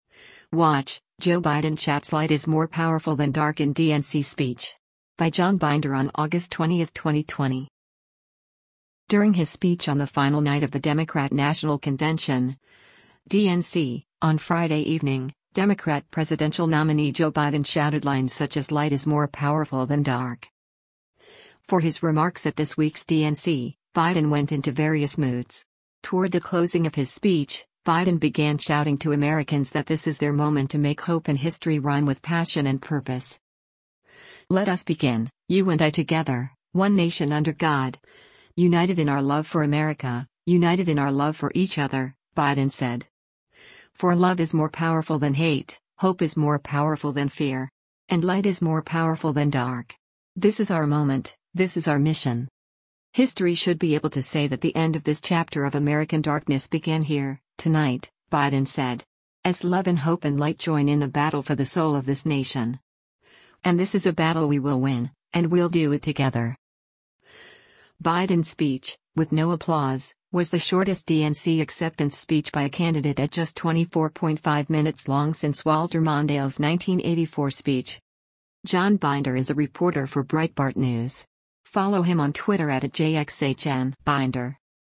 Watch: Joe Biden Shouts ‘Light Is More Powerful than Dark’ in DNC Speech
During his speech on the final night of the Democrat National Convention (DNC) on Friday evening, Democrat presidential nominee Joe Biden shouted lines such as “Light is more powerful than dark!”
Toward the closing of his speech, Biden began shouting to Americans that this is their “moment to make hope and history rhyme with passion and purpose.”
Biden’s speech, with no applause, was the shortest DNC acceptance speech by a candidate at just 24.5 minutes long since Walter Mondale’s 1984 speech.